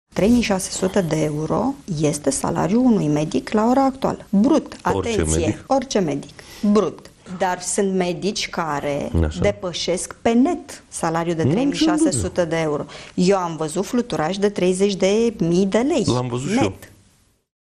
„3600 de euro este salariul unui medic la ora actuală. Brut, atenție! Orice medic. Brut! Dar sunt medici care depășesc pe net 3600 de euro. Eu am văzut fluturași de 30.000 de lei net”, spunea doamna Olguța Vasilescu, duminică, într-un interviu la România TV.